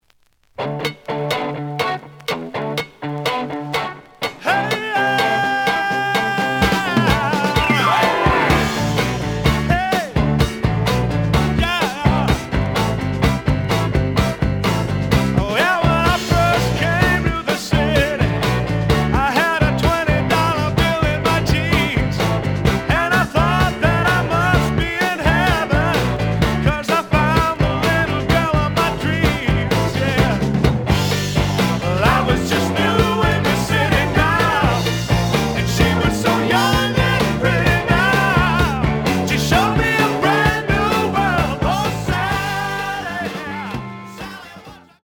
The audio sample is recorded from the actual item.
●Genre: Rock / Pop
Slight edge warp. But doesn't affect playing. Plays good.